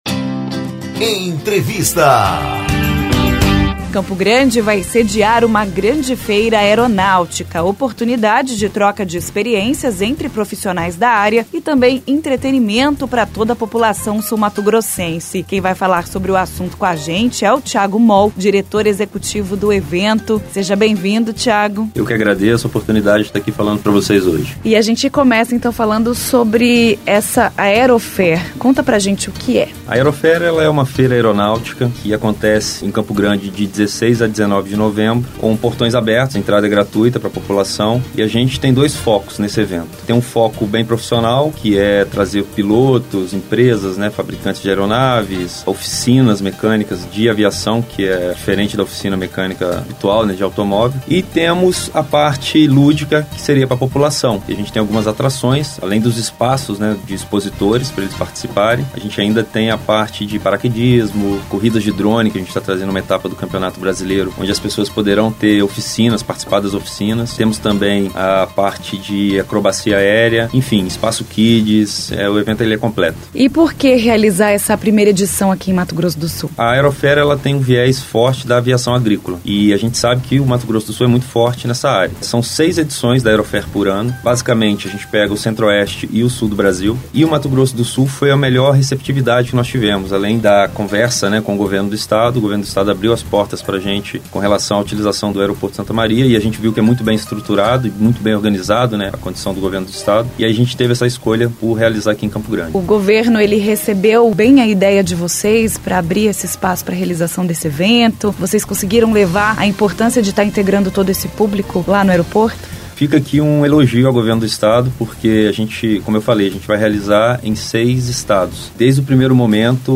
Entrevista: MS vai sediar feira aeronáutica em novembro – Agência de Noticias do Governo de Mato Grosso do Sul